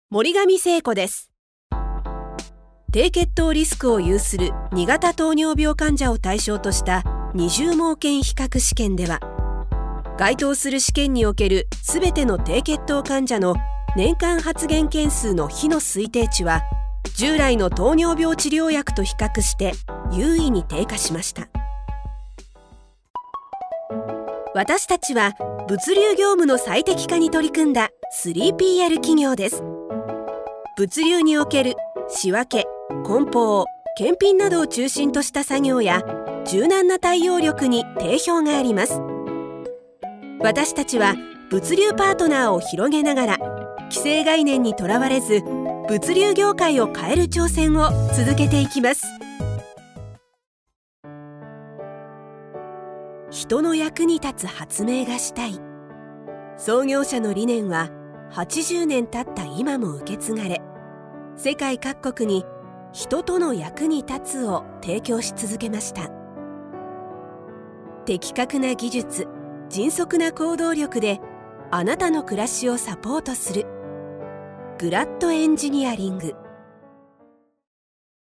ボイスサンプル
• 穏やかでまろやかな声
• 音域：高～中音
• 声の特徴：穏やか、明るい、説得力